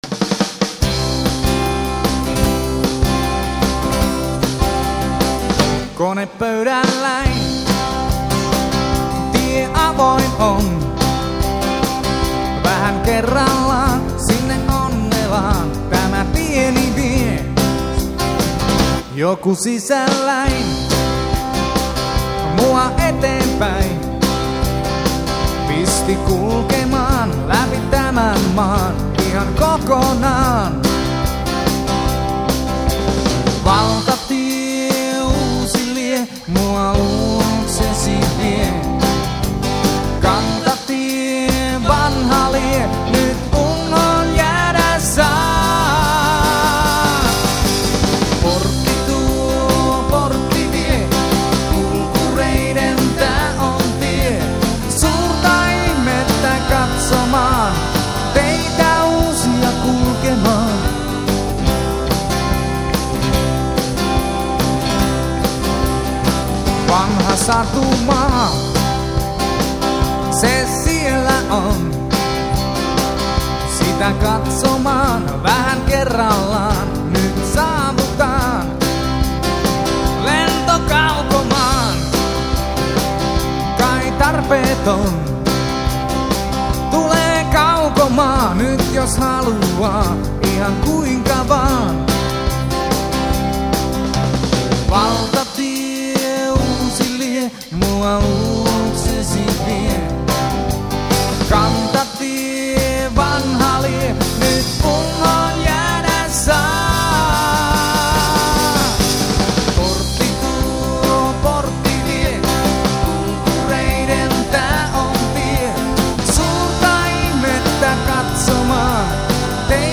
Live!